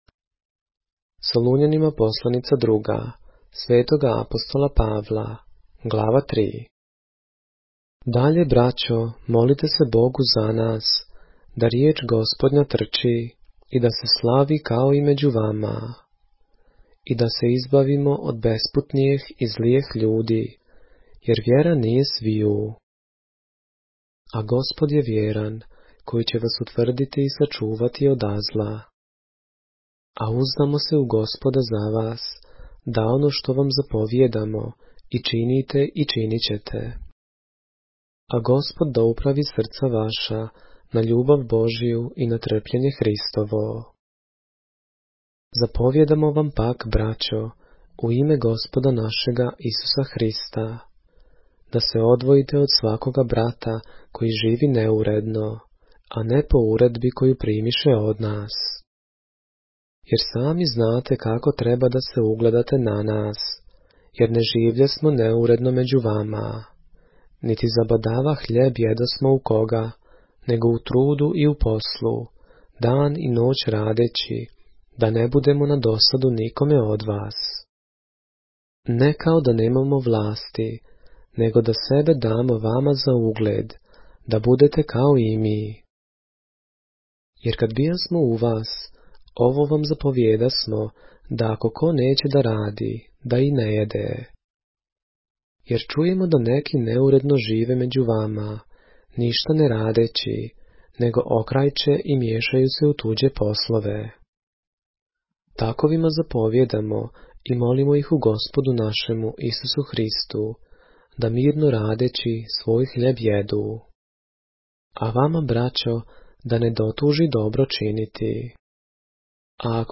поглавље српске Библије - са аудио нарације - 2 Thessalonians, chapter 3 of the Holy Bible in the Serbian language